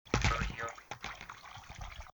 fishing_bucket.ogg